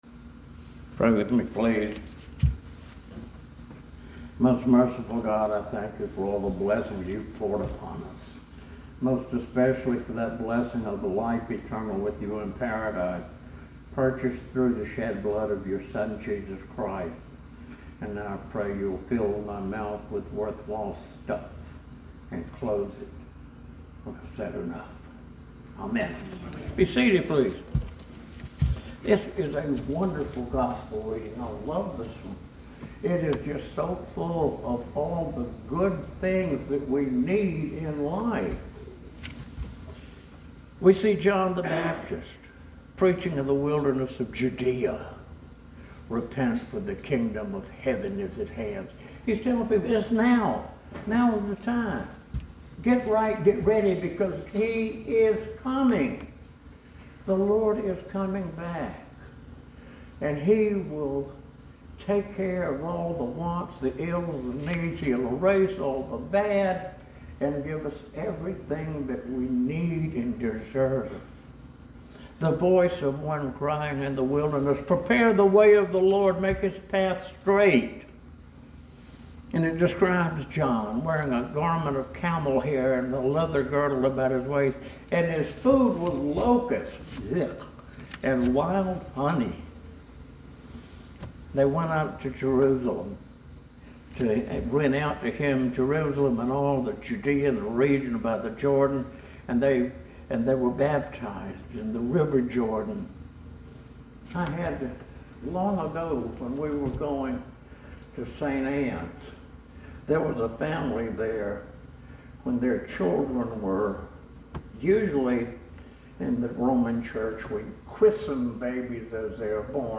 SERMON OF THE WEEK